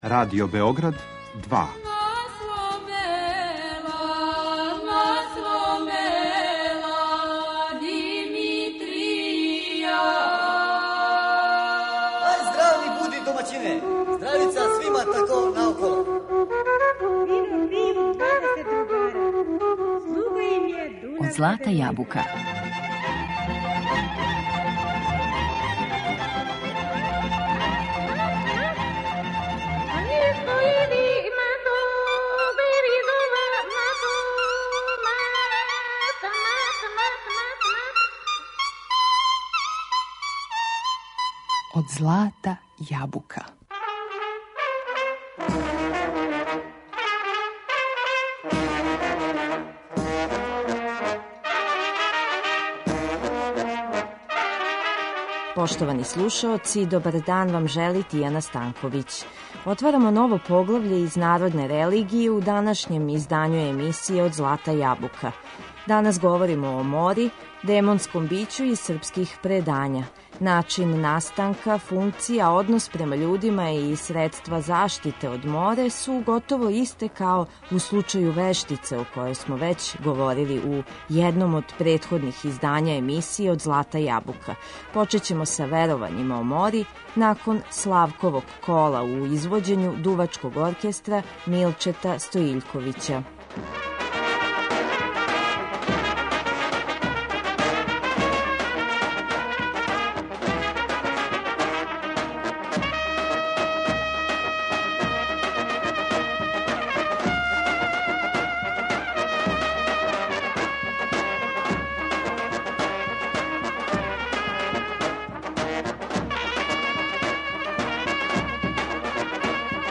Шта је њена функција, какав је њен однос према људима и која су средства заштите од овог демонског бића из народне религије? На репертоару су вокални солисти уз пратњу Народног оркестра Радио Београда, инструменталне мелодије у извођењу Великог народног оркестра и наших дувачких оркестара.